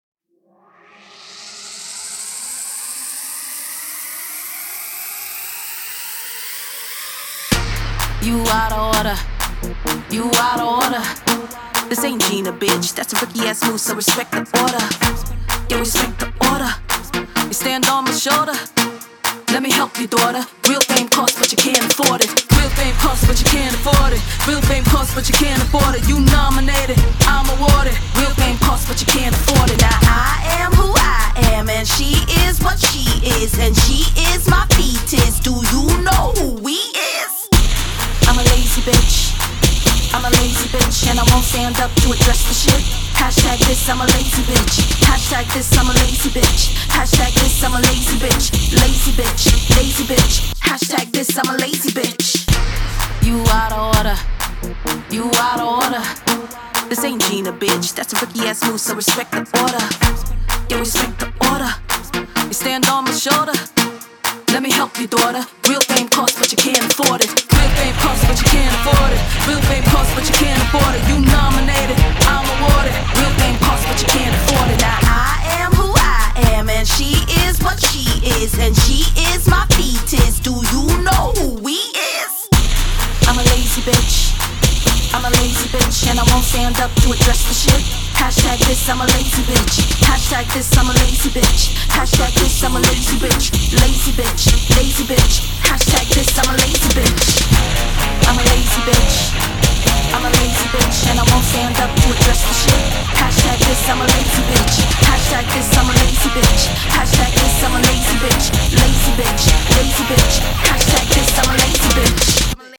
Hiphop
this track GOES HARD As FCK!